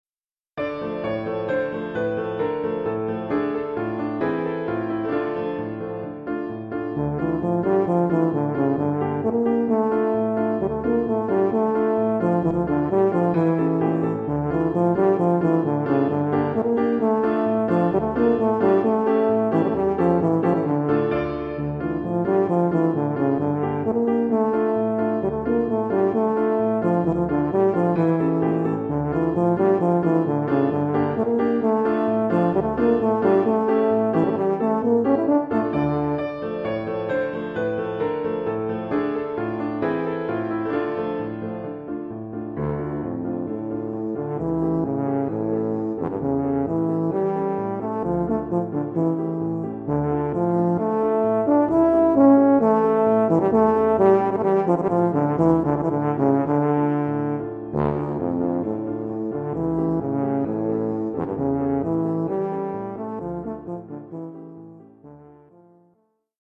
tuba et piano.